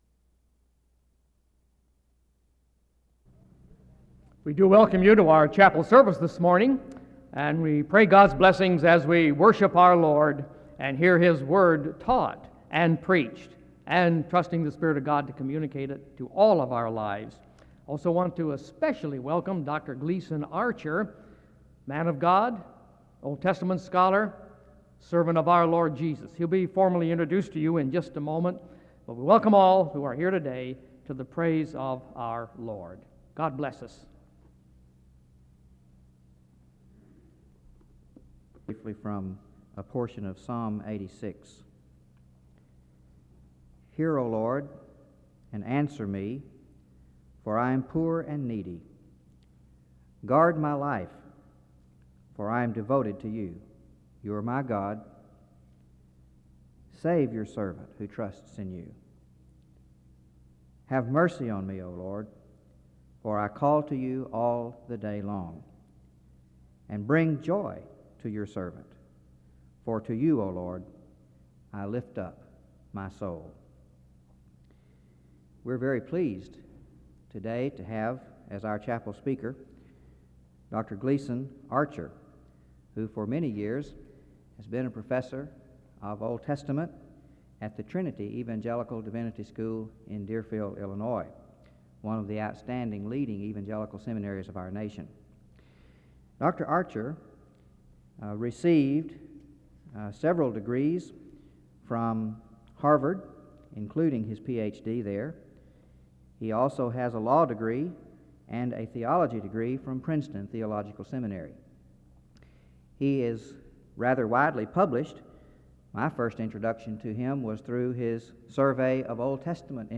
The audio was transferred from audio cassette. The service opens with announcements, prayer, introduction and a Scripture reading of Psalm 86 from 0:03-5:07.
He encourages the students to live a life that glorifies God's name. There are closing remarks and dismissal from 24:10-24:26.